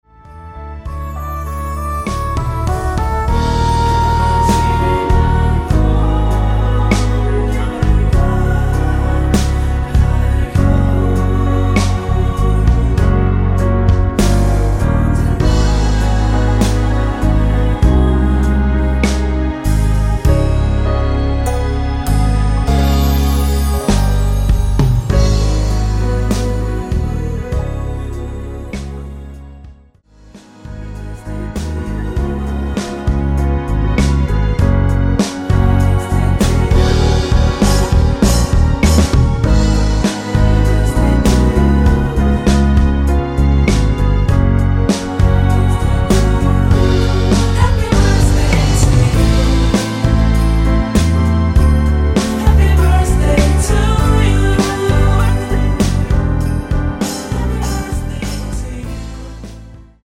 코러스 포함된 MR 입니다.(미리듣기 참조)
F#
◈ 곡명 옆 (-1)은 반음 내림, (+1)은 반음 올림 입니다.
앞부분30초, 뒷부분30초씩 편집해서 올려 드리고 있습니다.